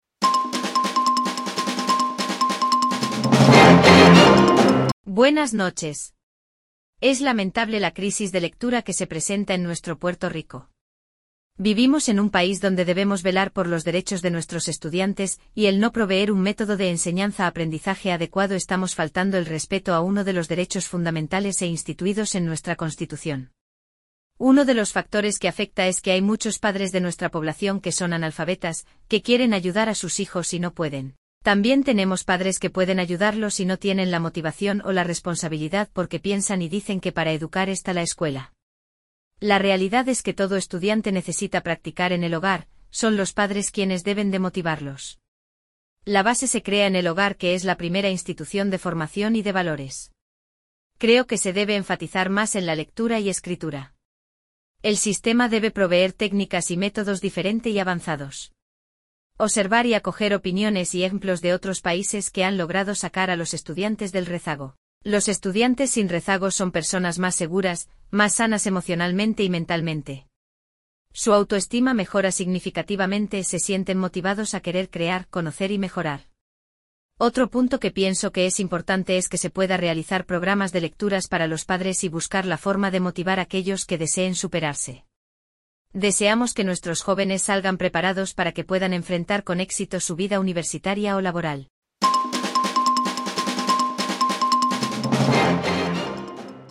En el podcast una maestra presenta testimonio de la situación real que se vive en una escuela publica de Puerto Rico donde pasan de grado a los estudiantes que no saben leer ni escribir.